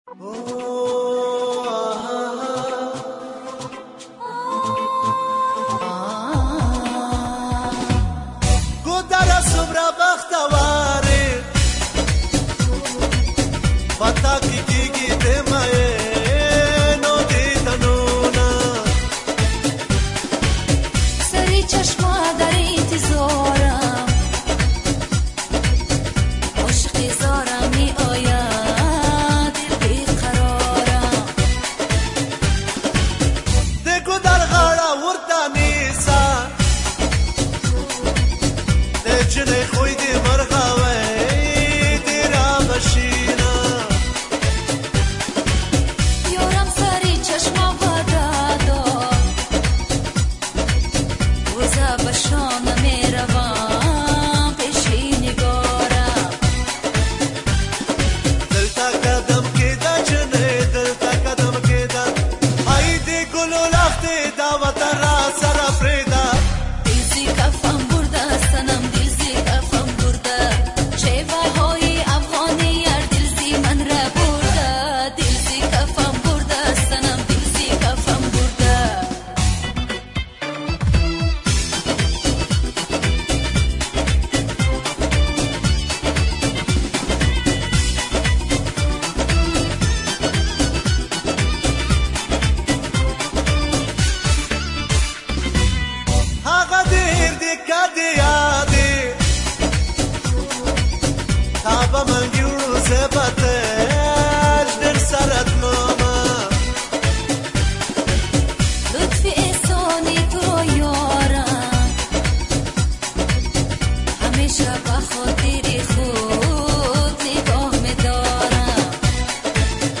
Категория: Эстрада